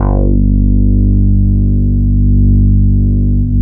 71 BASS   -L.wav